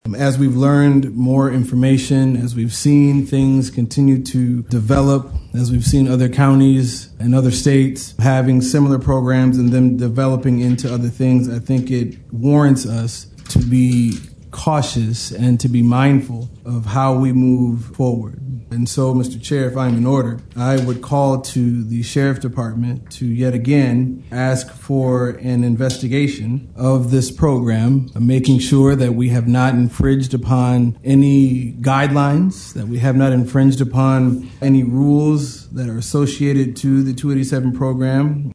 Jackson, Mich. (WKHM) — The Jackson County Board of Commissioners meeting on Tuesday night featured a packed room and multiple hours of public comment regarding the Sheriff’s Office’s (JCSO) 287(g) Agreement with ICE.
Commissioner Darius Williams requested that the JCSO conduct an investigation into the agreement, saying he would like to see how many officers have received specialized training and potential costs associated.